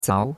zao2.mp3